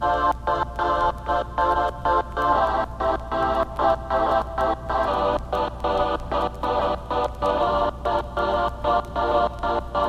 很适合于欢快的嘻哈和寒假的器乐
Tag: 95 bpm Hip Hop Loops Choir Loops 1.70 MB wav Key : C